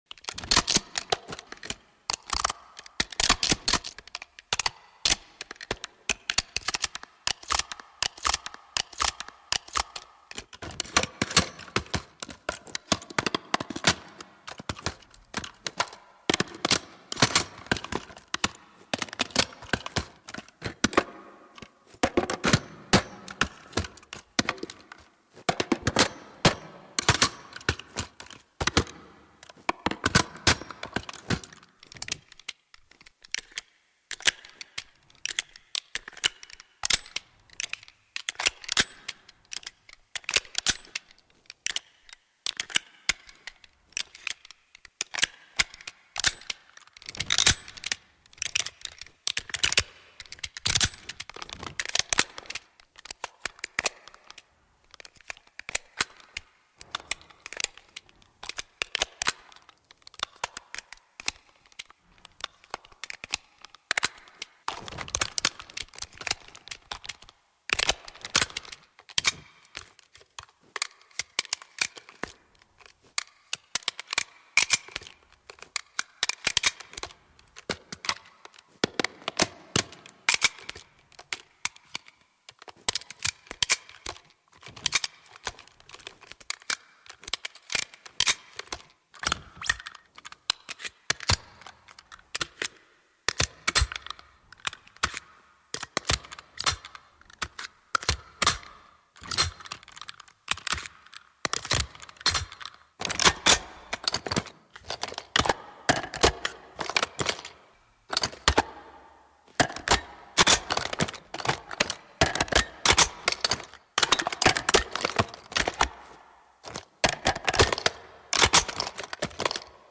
Black Ops 6 - All Reload Animations